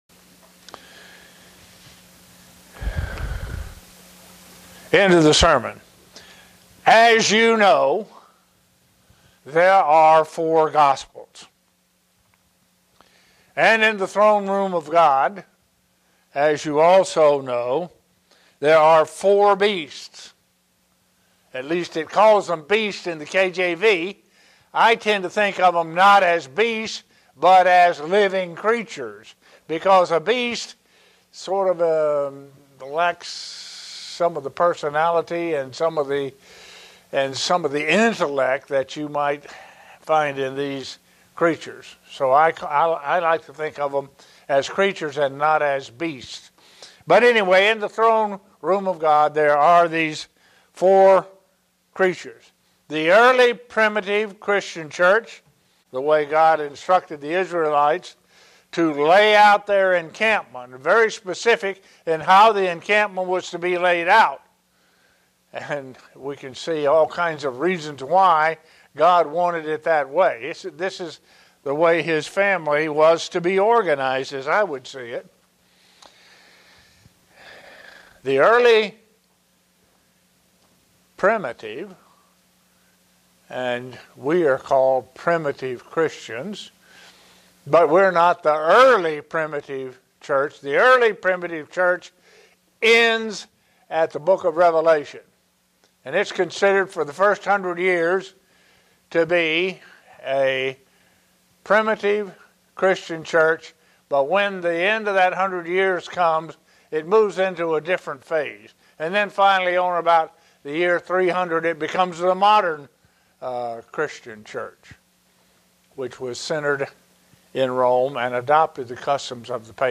Given in Buffalo, NY